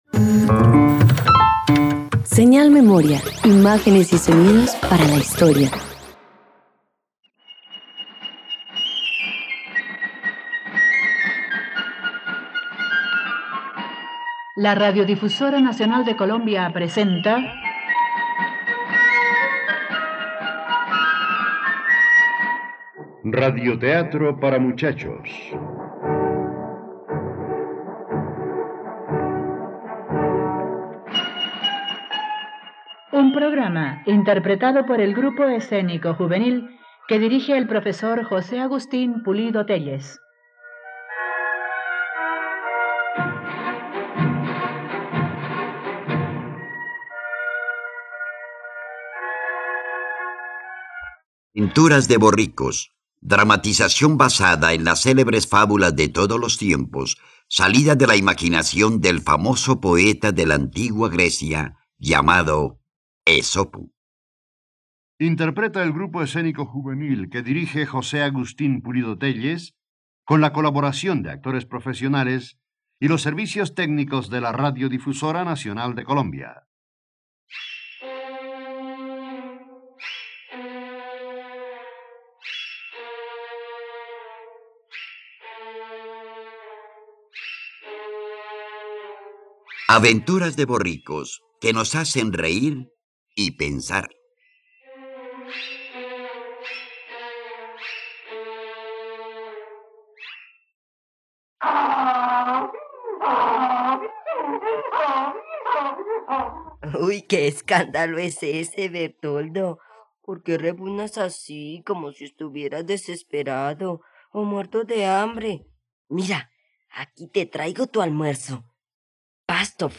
Aventuras de borricos - Radioteatro dominical | RTVCPlay